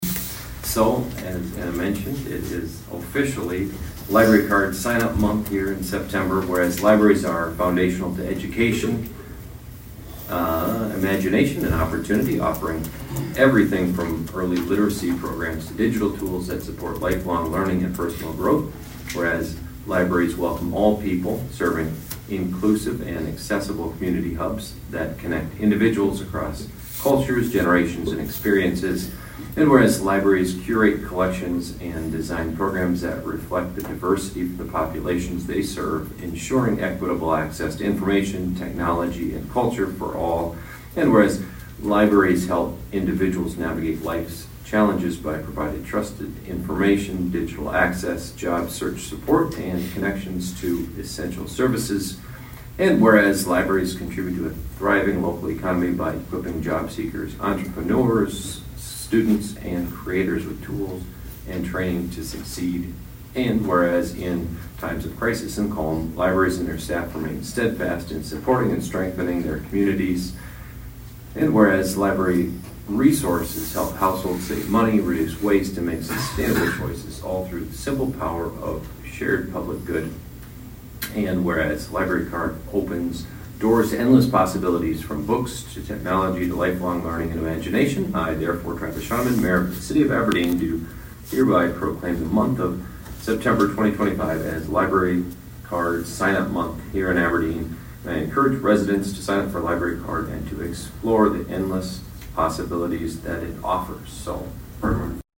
Aberdeen Mayor read the proclamation.(1:45)
This was part of the Consent Calendar at the start of the meeting and was approved by the council.